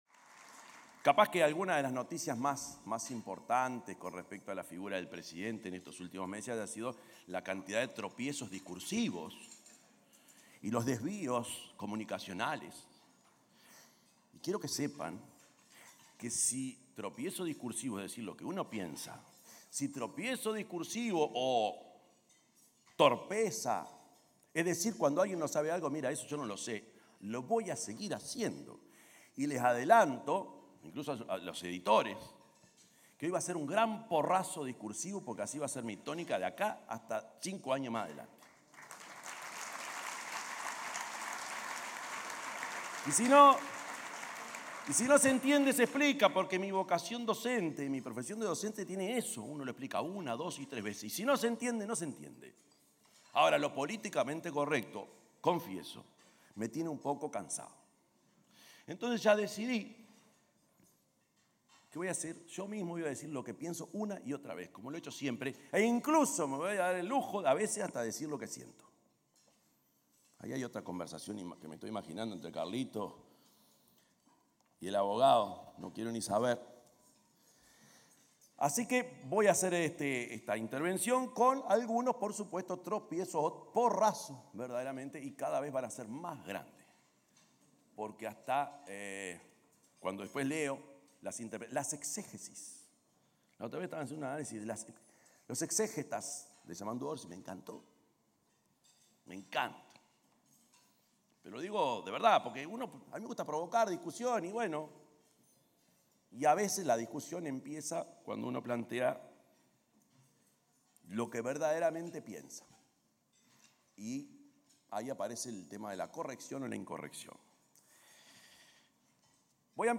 Palabras del presidente de la República, Yamandú Orsi
Palabras del presidente de la República, Yamandú Orsi 09/12/2025 Compartir Facebook X Copiar enlace WhatsApp LinkedIn El presidente de la República, Yamandú Orsi, detalló el grado de cumplimiento de las prioridades quinquenales del Gobierno definidas en el primer Consejo de Ministros, en el almuerzo organizado por la Asociación de Dirigentes de Marketing (ADM), este martes 9, en el hotel Radisson.